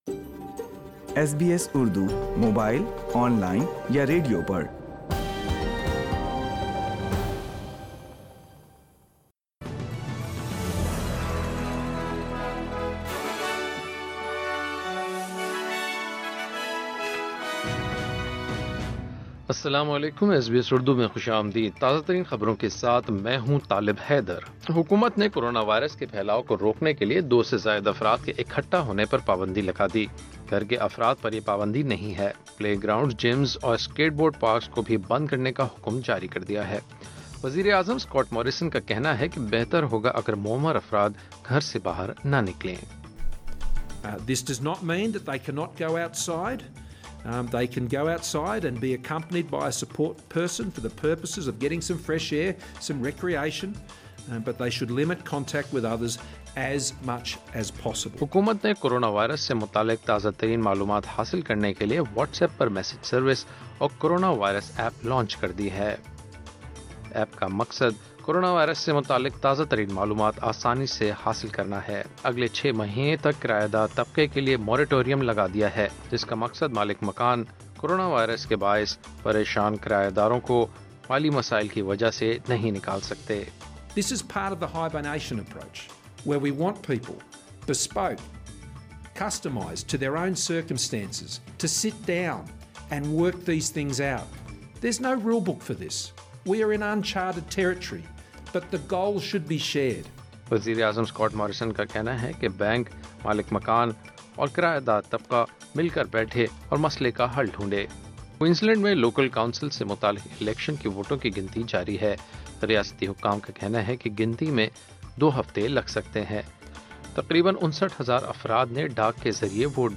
ایس بی ایس اردو خبریں ۳۰ مارچ ۲۰۲۰